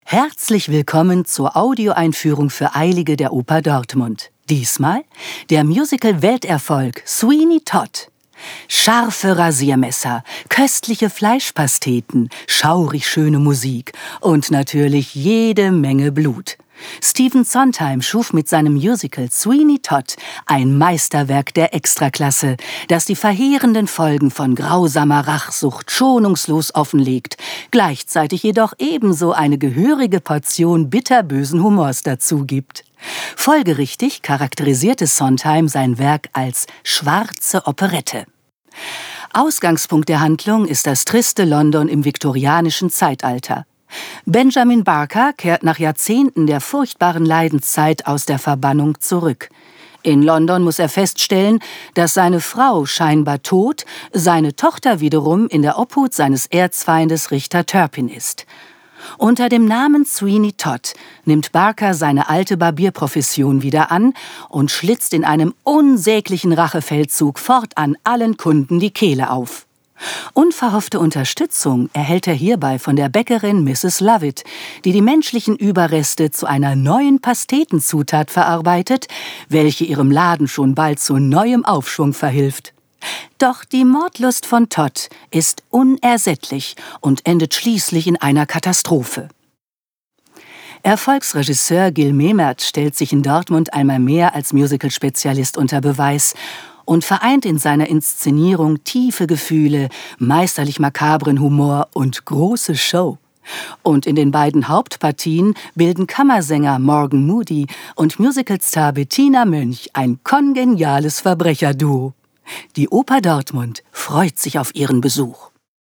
tdo_Audioeinfuehrung_Sweeney_Todd.mp3